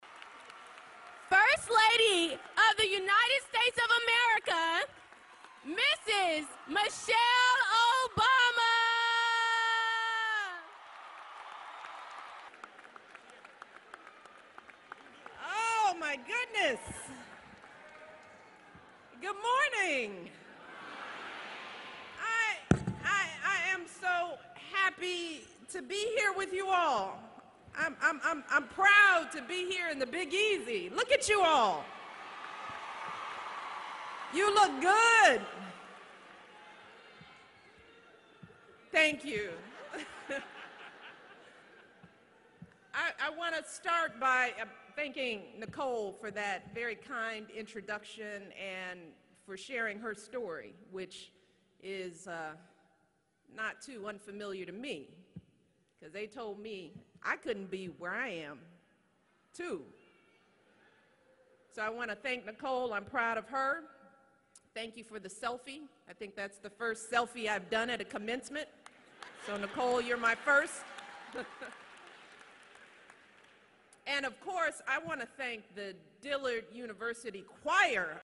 公众人物毕业演讲 第76期:米歇尔奥巴马迪拉德大学(2) 听力文件下载—在线英语听力室